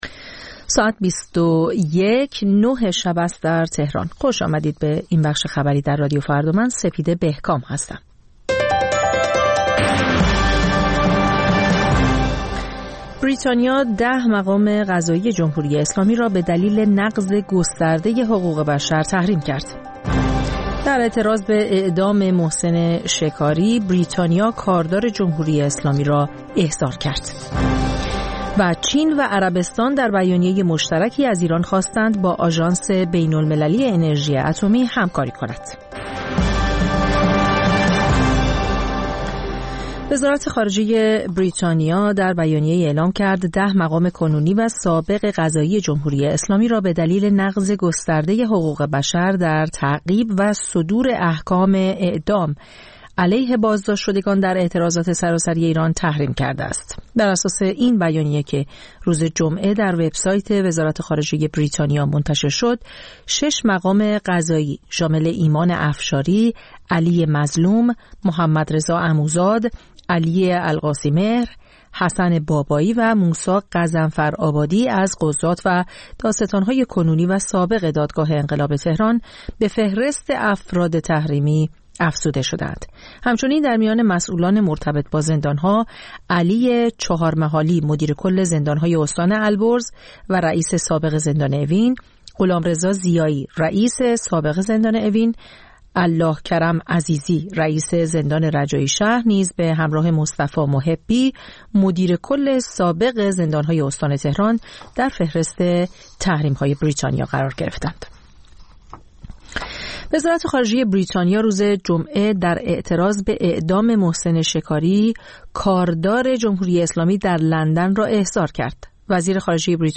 خبرها و گزارش‌ها ۲۱:۰۰